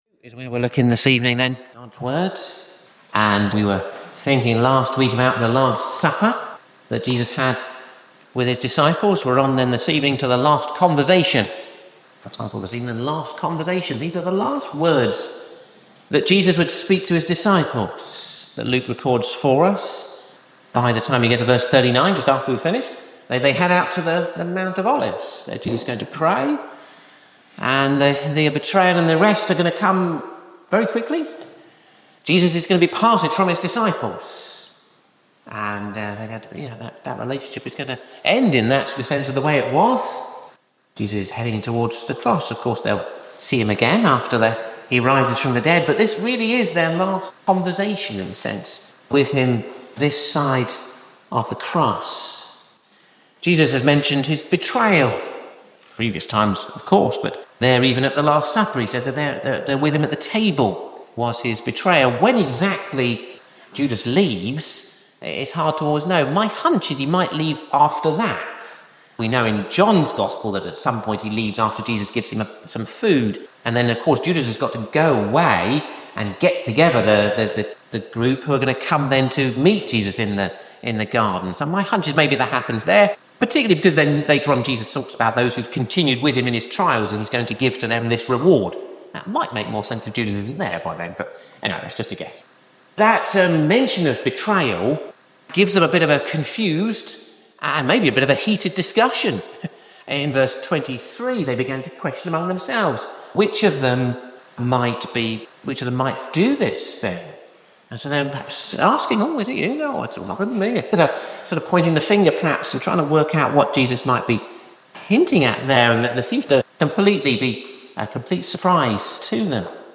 Sermons: Ashbourne Baptist Church 2025 | (Hope For Ashbourne YouTube channel)